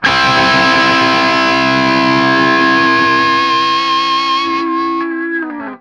TRIAD C   -L.wav